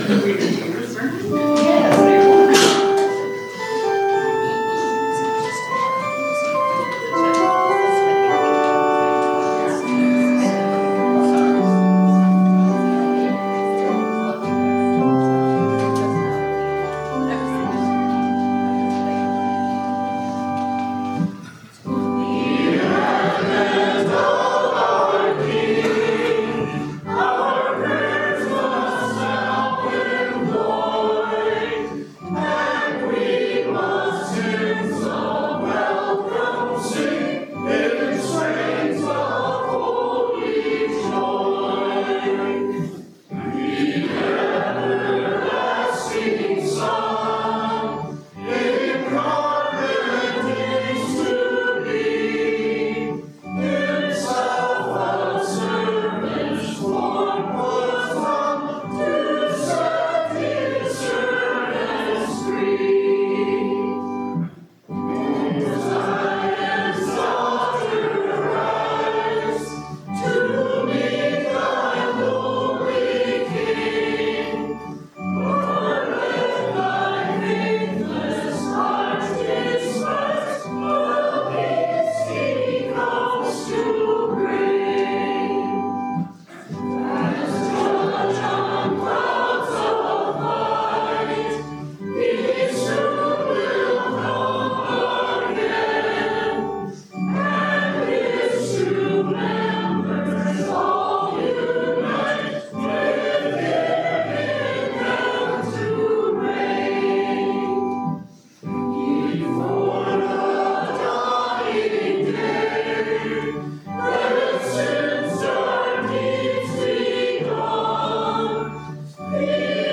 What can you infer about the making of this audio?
Worship Audio – Zion Evangelical Lutheran Church (LCMS) Crosby, Minnesota Zion-Worship-1-Dec-24.mp3